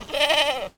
goat_call_01.wav